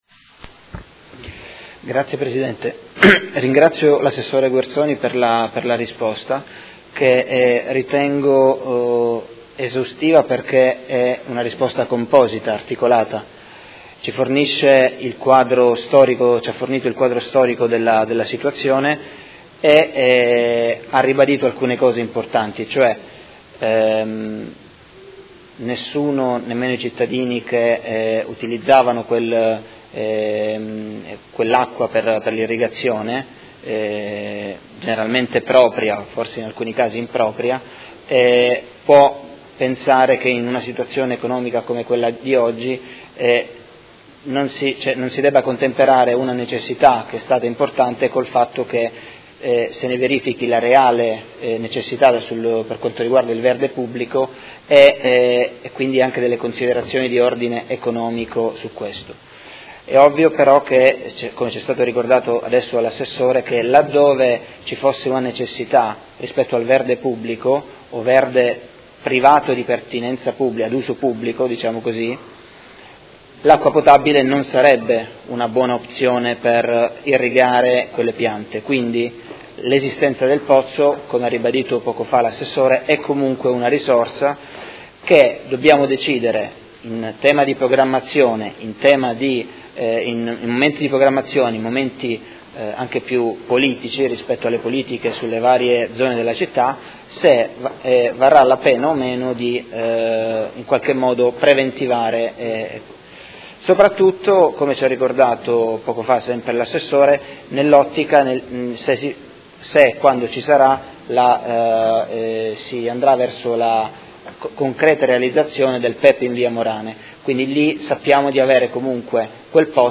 Seduta del 20/04/2016. Conclude interrogazione del Consigliere Fasano (P.D.) avente per oggetto: Stato dell’impianto di irrigazione del 3° comprensorio PEEP (pozzo di Via Grosseto)